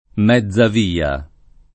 Mezzavia [ m Hzz av & a ]